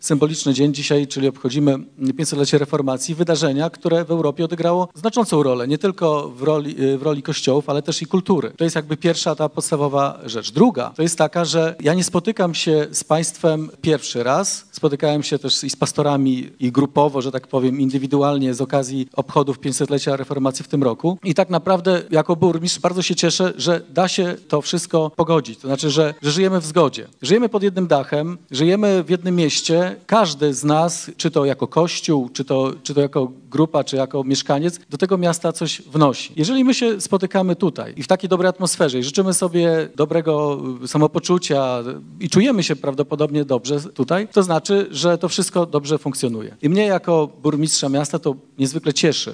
Wspólne nabożeństwo dziękczynne wszystkich protestantów z okazji 500-lecia reformacji w Kościele Zielonoświątkowym odbyło się we wtorek (31.10.) w Giżycku.
– Jako włodarz cieszę się z tego spotkania z kilku powodów – mówił Iwaszkiewicz.